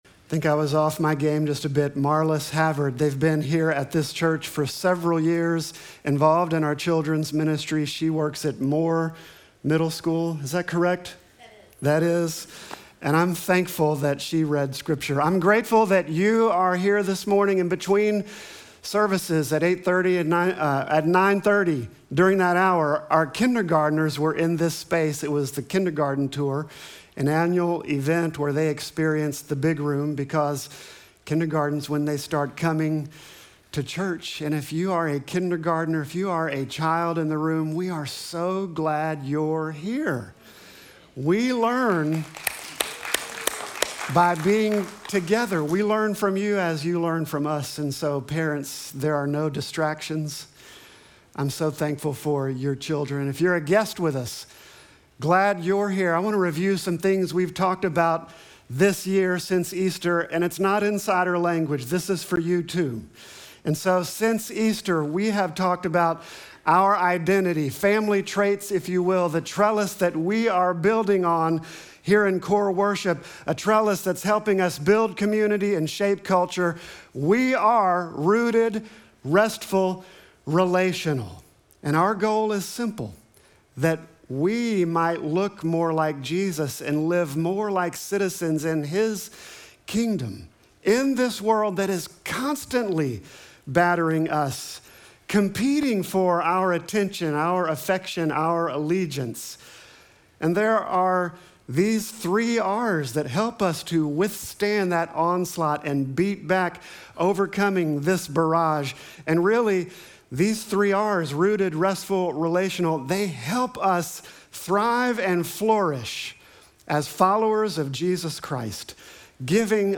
Sermon text: Luke 22:14-20